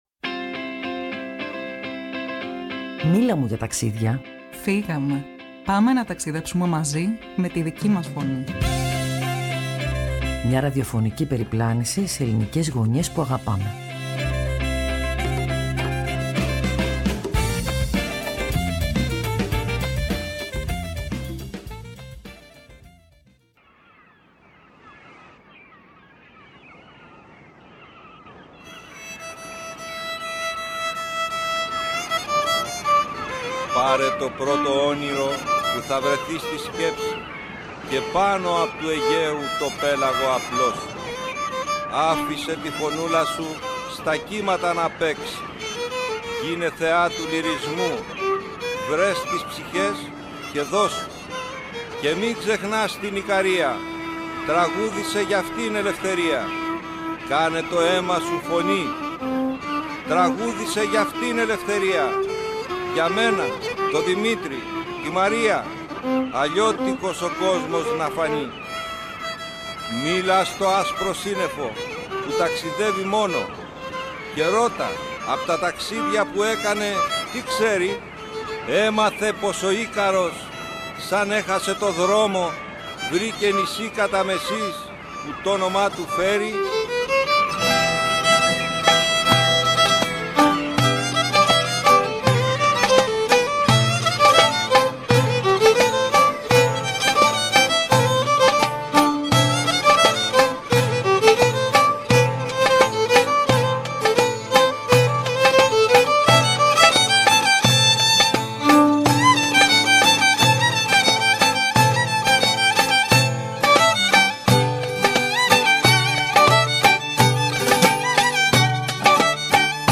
Μέσα από τρεις θεματικές ενότητες – Ιστορία, Σύγχρονη Πραγματικότητα και Πολιτισμός – τρεις ξεχωριστοί Ικαριώτες μας μίλησαν για έναν τόπο που δεν σταμάτησε ποτέ να αντιστέκεται, να θυμάται, να δημιουργεί: